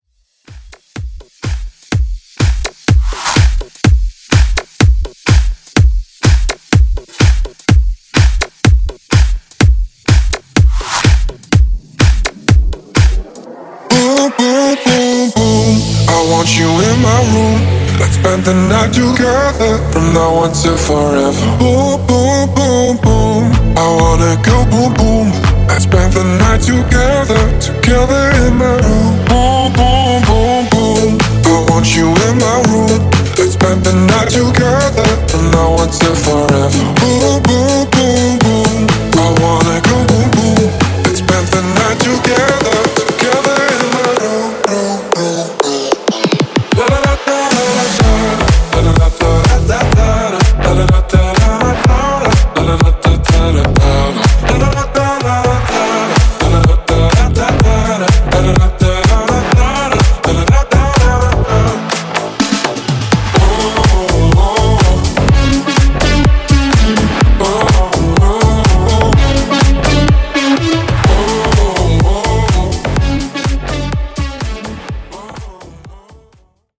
Club In-Outro)Date Added